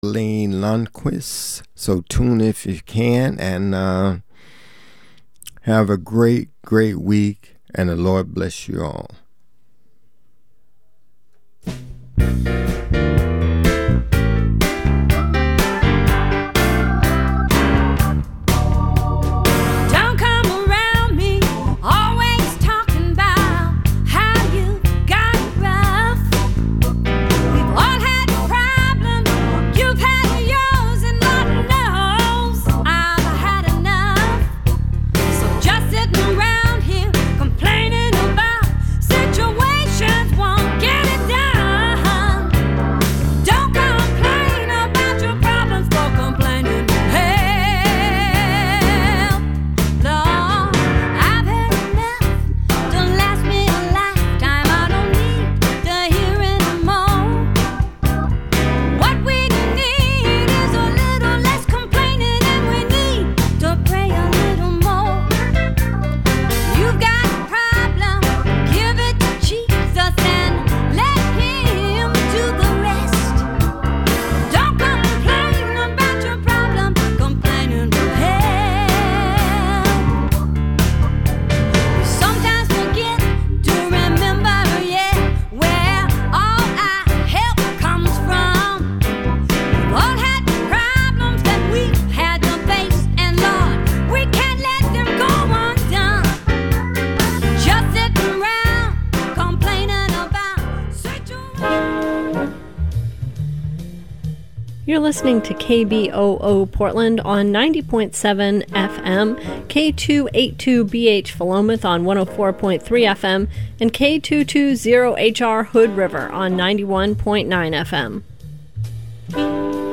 Conversations with leaders in personal and cultural transformation